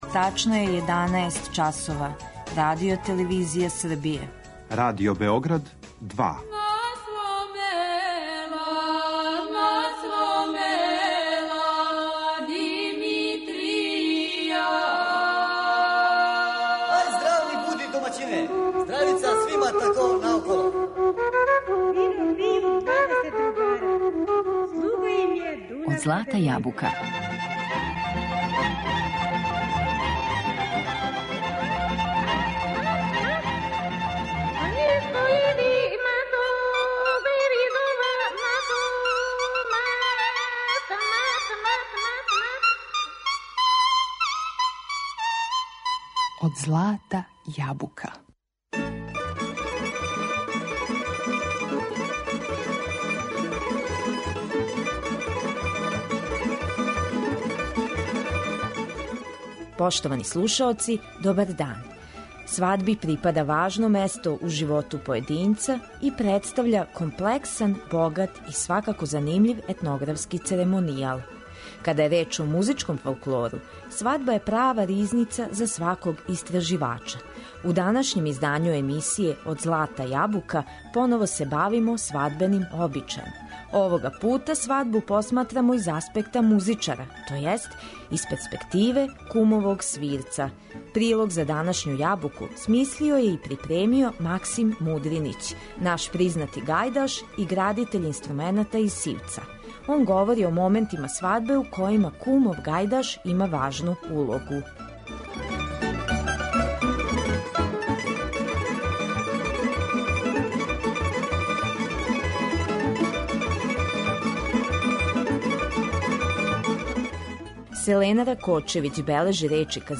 Зато у емисији слушамо сватовце и бећарце, као и по које коло, типично за војвођанску свадбу и војвођанске свирце.